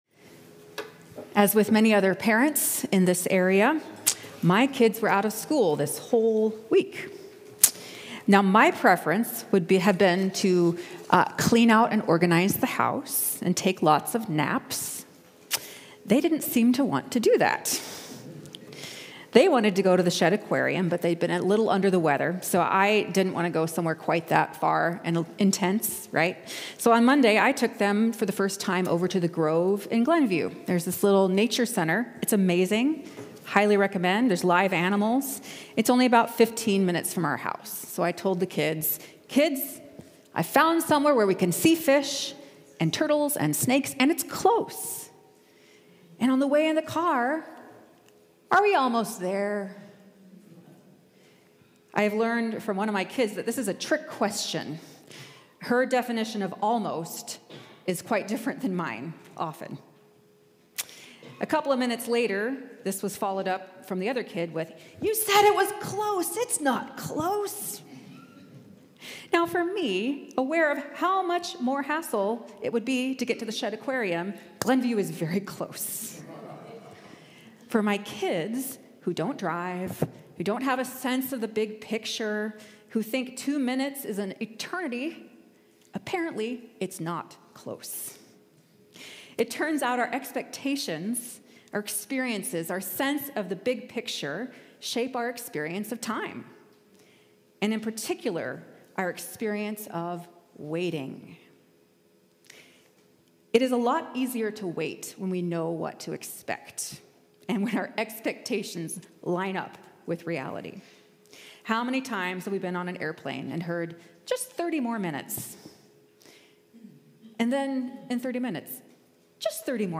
1 COR Sermon – December 1, 2024 23:02